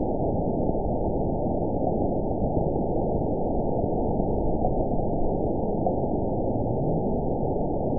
event 920560 date 03/30/24 time 06:05:56 GMT (1 year, 1 month ago) score 8.66 location TSS-AB01 detected by nrw target species NRW annotations +NRW Spectrogram: Frequency (kHz) vs. Time (s) audio not available .wav